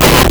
Player_Glitch [32].wav